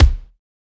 BEAT KICK 06.WAV